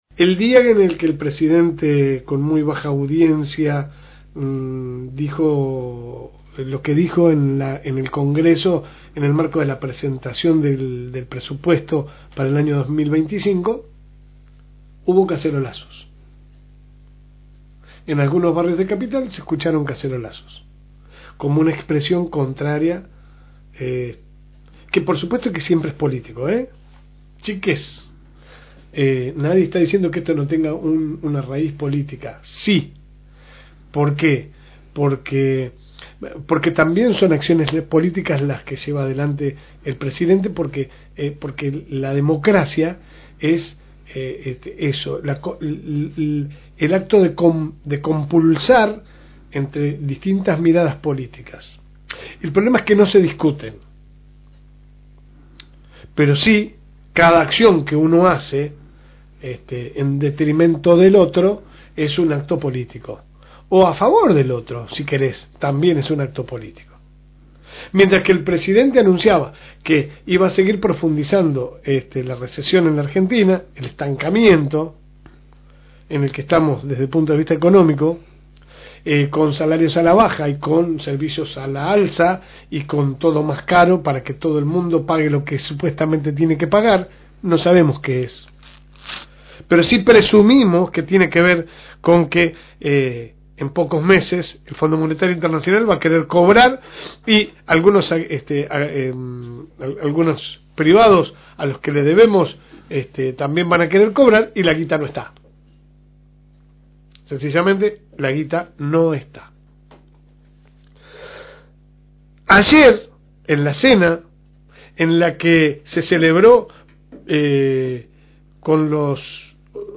AUDIO – Editorial de La Segunda Mañana – FM Reencuentro
La Segunda Mañana sale por el aire de la Fm Reencuentro 102.9 de lunes a viernes de 10 a 12 HS.